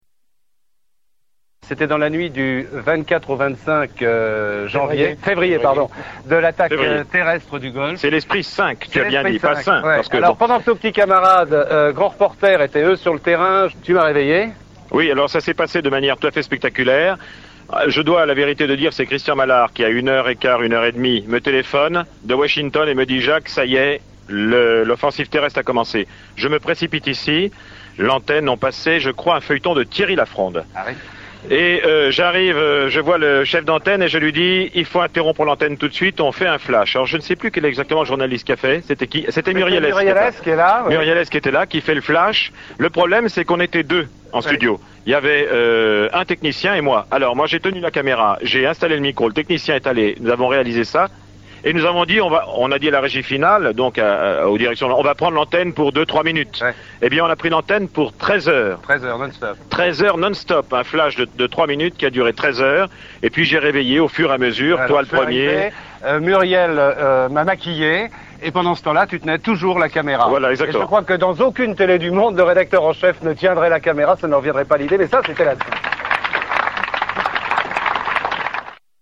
Des journalistes évoquent le flash spécial de la guerre du golfe ( 01:19 - MP3 )